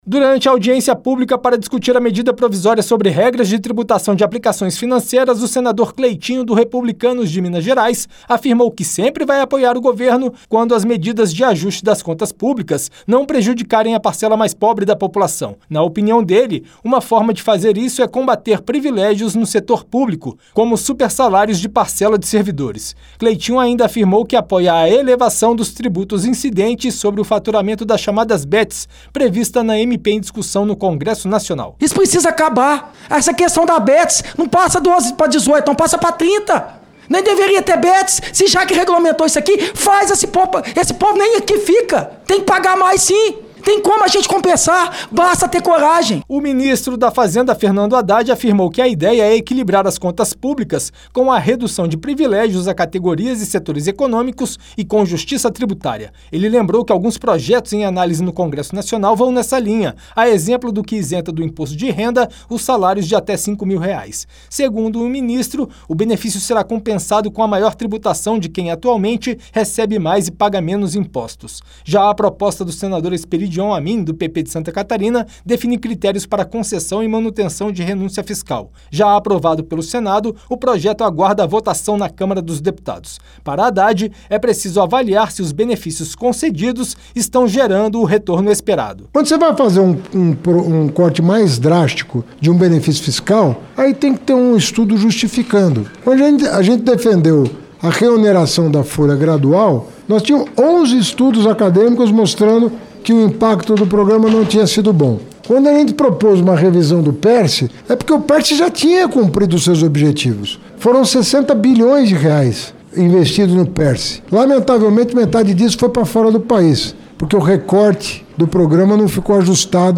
Em audiência pública para discutir a medida provisória que trata da tributação de aplicações financeiras (MPV 1303/2025), o senador Cleitinho (Republicanos-MG) defendeu que o ajuste das contas públicas não recaia sobre o trabalhador. Na ocasião, o ministro da Fazenda, Fernando Haddad, afirmou que a ideia do governo é equilibrar as finanças e promover justiça tributária.